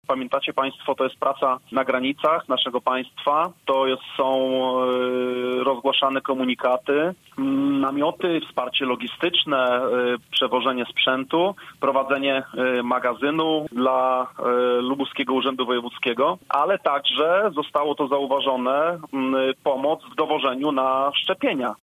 - To najlepsza okazja, by docenić ich ciężką służbę - mówi w Radiu Zachód Patryk Maruszak.
Komendant wojewódzki państwowej straży pożarnej powiedział, że od początku epidemii pracy jest więcej.
poranny-gosc-maruszak-1.mp3